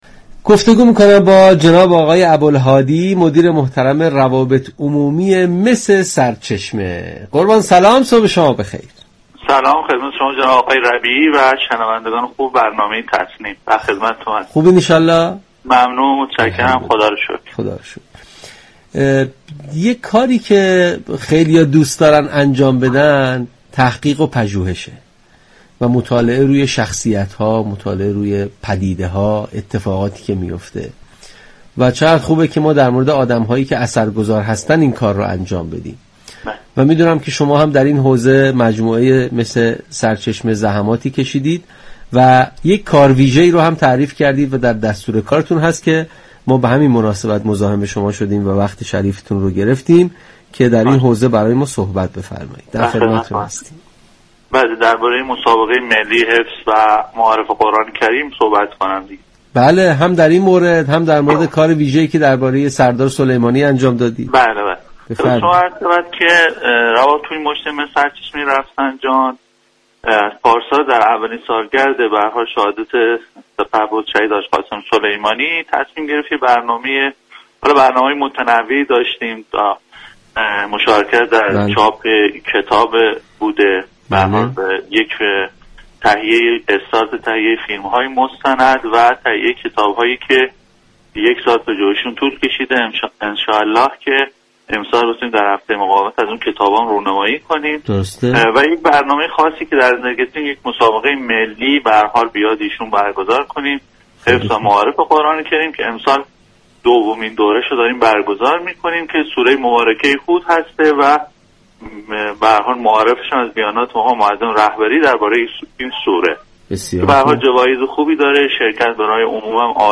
در گفتگو با برنامه تسنیم رادیو قرآن